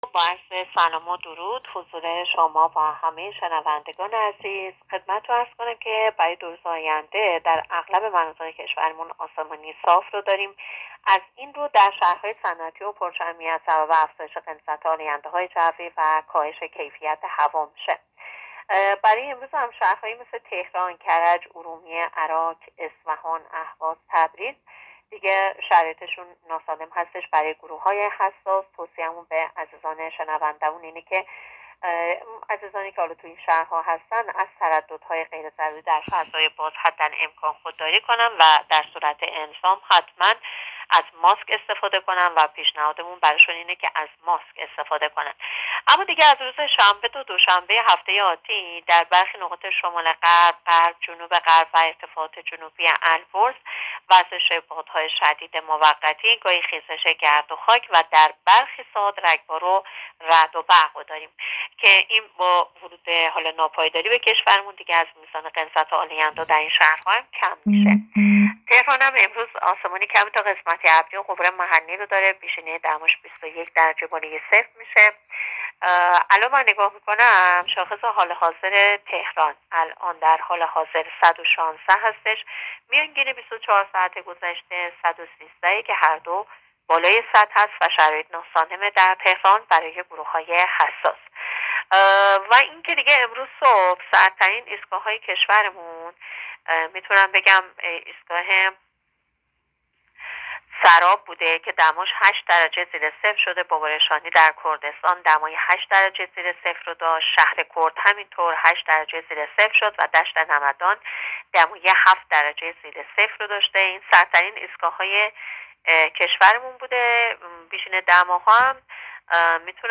گزارش رادیو اینترنتی پایگاه‌ خبری از آخرین وضعیت آب‌وهوای ۲۲ آبان؛